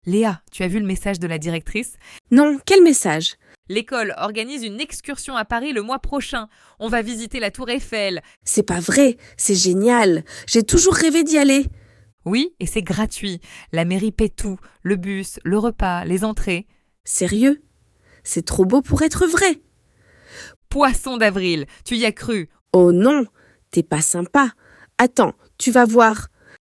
Vocabular, negația la oral (ce dispare?), dialog autentic între doi prieteni, expresia „tomber dans le panneau" și tradiții din 6 țări.
ElevenLabs_Poisson_d-avril_newsletter_EduMNC.mp3